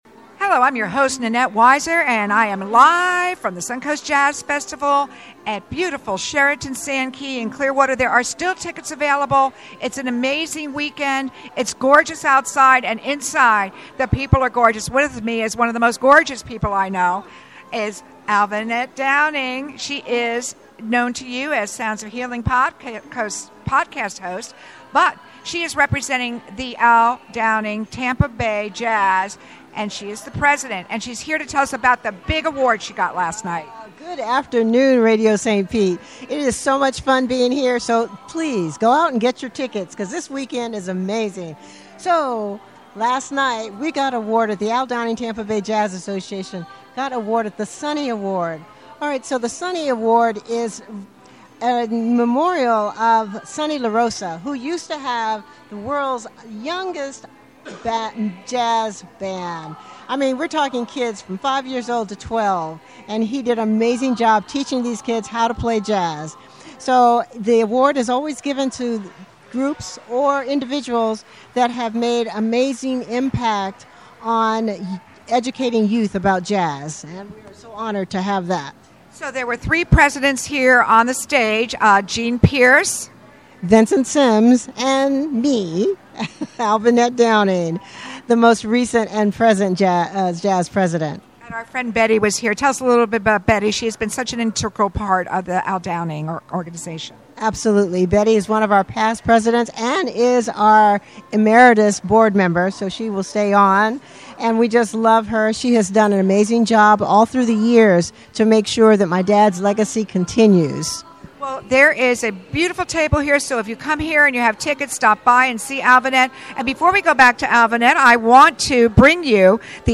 SUNCOAST JAZZ FESTIVAL LIVE!